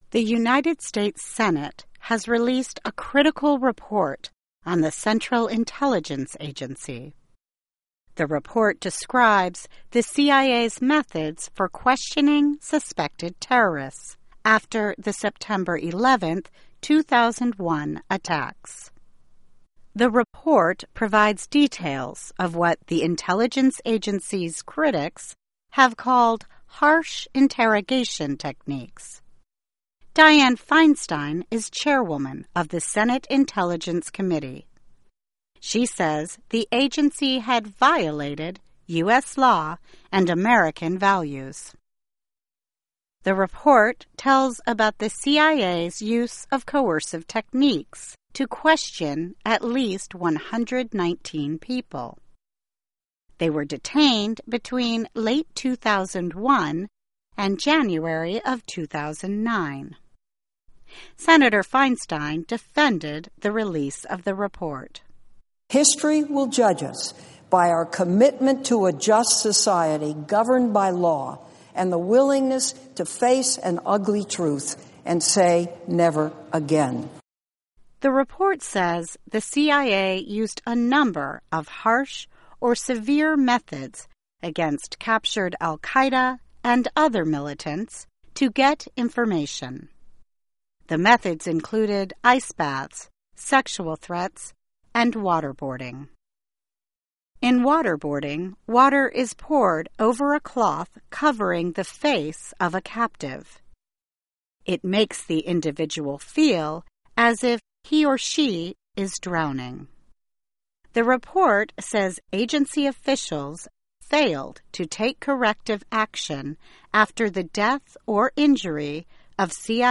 by VOA - Voice of America English News